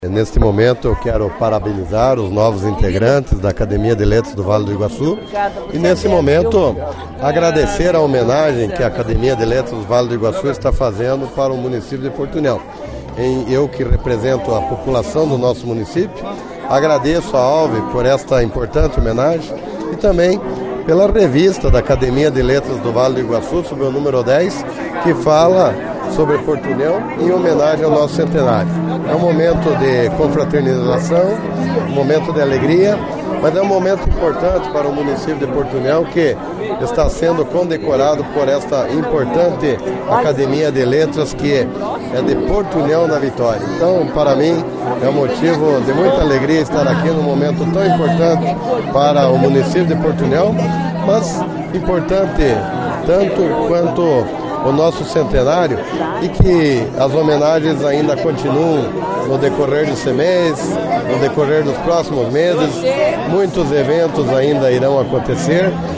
A programação em homenagem ao município de Porto União, pelos seus 100 anos, teve continuidade na noite dessa sexta-feira, 22 de setembro, no salão nobre do Colégio Santos Anjos.
O prefeito de Porto União, Eliseu Mibach, em sua fala agradeceu a ALVI, pela homenagem da entidade ao povo de Porto União, pelo seu Centenário e disse que o momento é de muita festa e parabenizou aos novos membros da entidade.